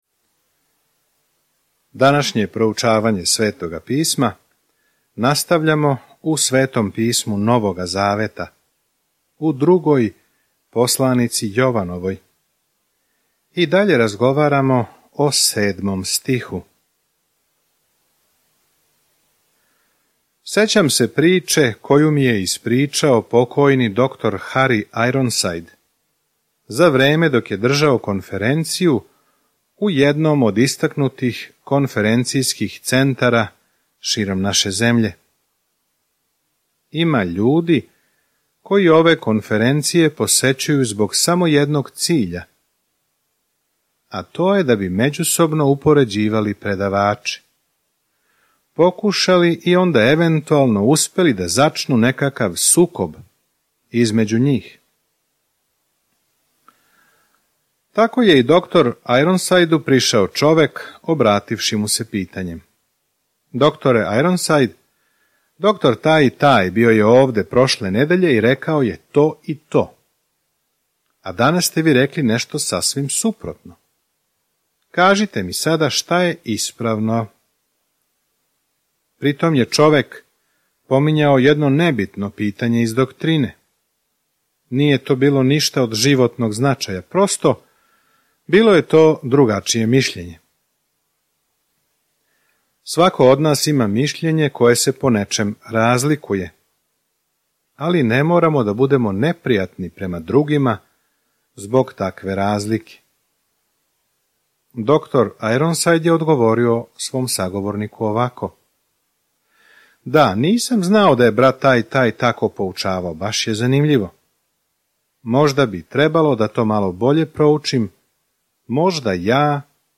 Jovanova 1:8-10 Dan 4 Započni ovaj plan Dan 6 O ovom planu Ово друго Јованово писмо помаже великодушној жени и локалној цркви да знају како да изразе љубав унутар граница истине. Свакодневно путујте кроз 2. Јованову док слушате аудио студију и читате одабране стихове из Божје речи.